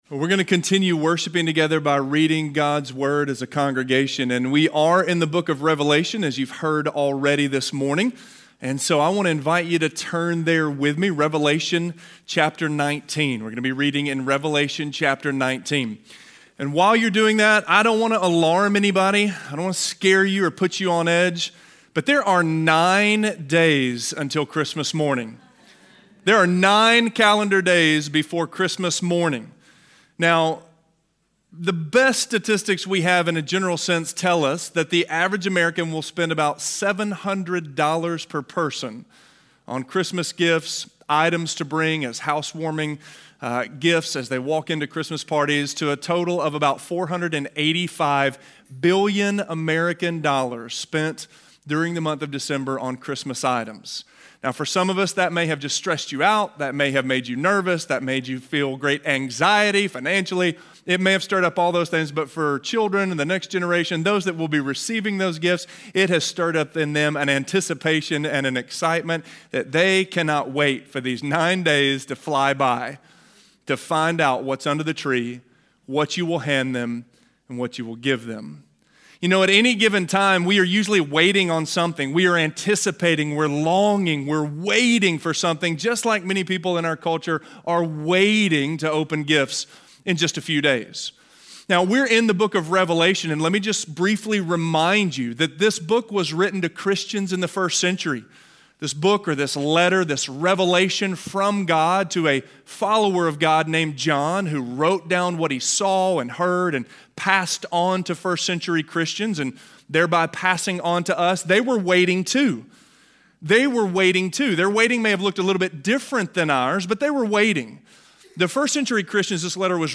The Last Battle - Sermon - Avenue South